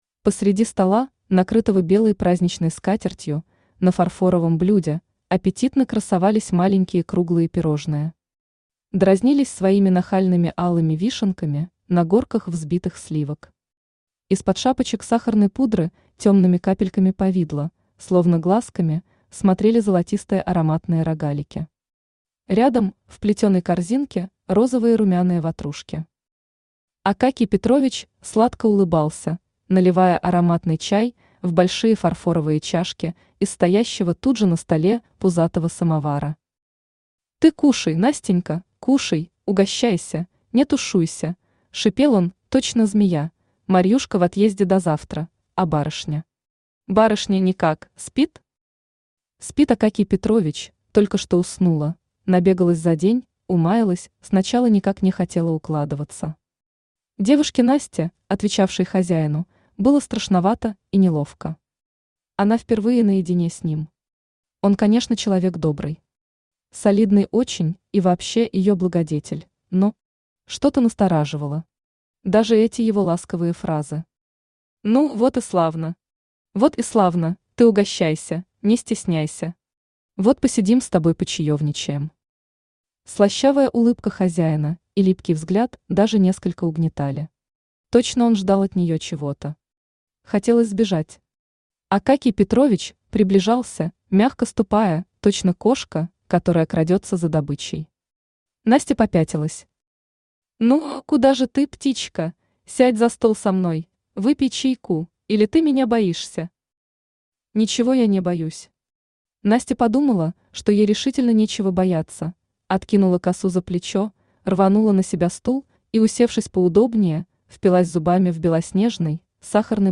Аудиокнига Необоримая стена | Библиотека аудиокниг
Aудиокнига Необоримая стена Автор Лариса Черногорец Читает аудиокнигу Авточтец ЛитРес.